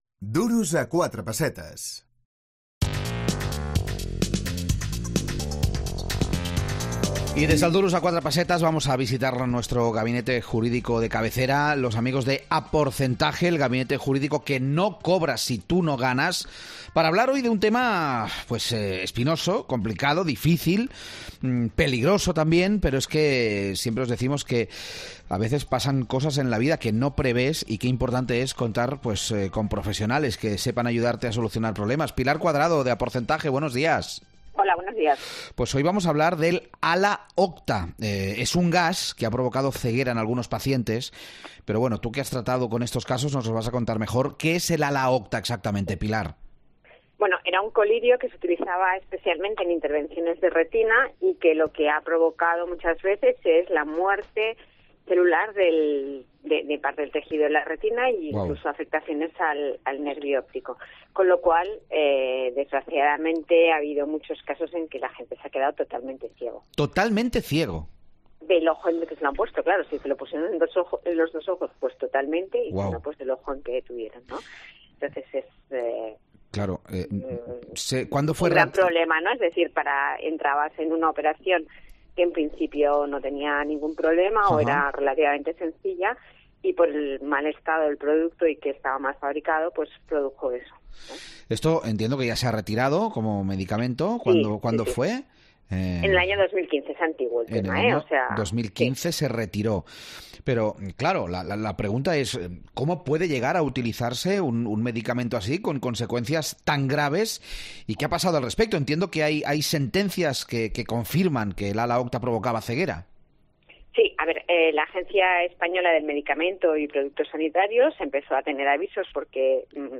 Lo analizamos con el gabinete jurídico "Aporcentaje"